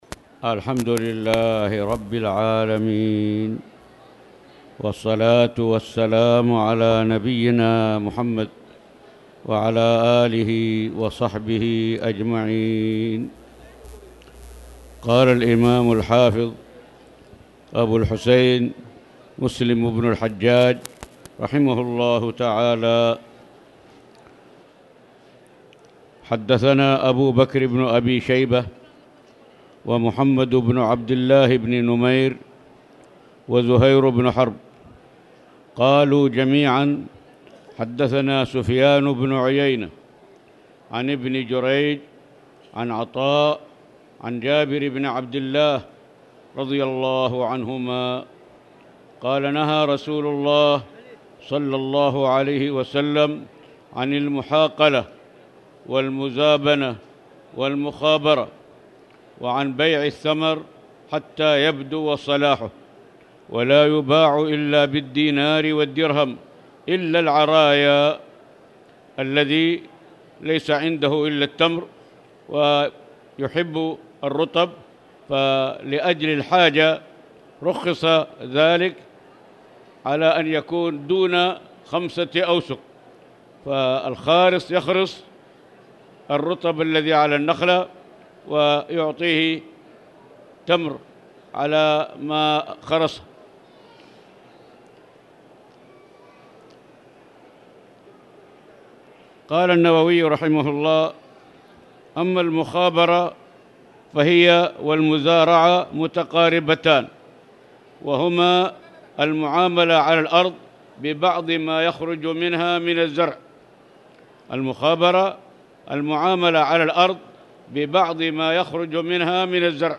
تاريخ النشر ١٨ ربيع الثاني ١٤٣٨ هـ المكان: المسجد الحرام الشيخ